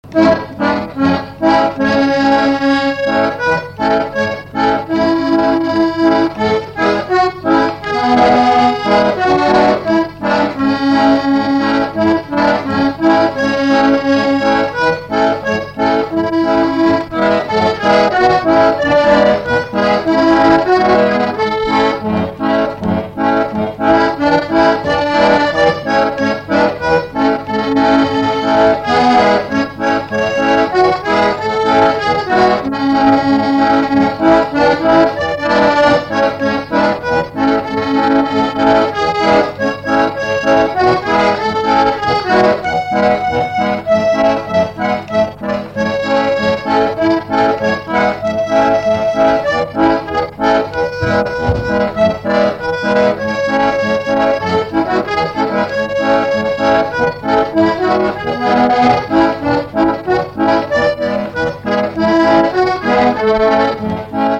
Instrumental
circonstance : fiançaille, noce
Pièce musicale inédite